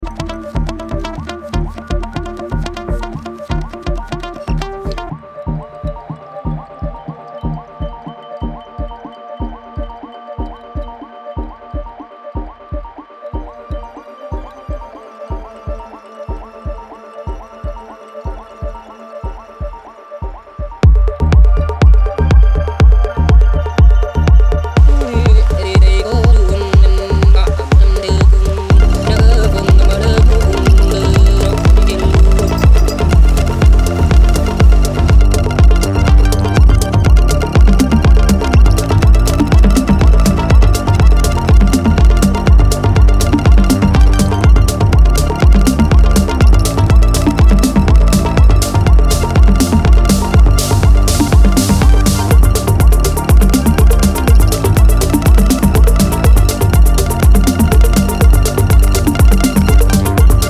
electronic sounds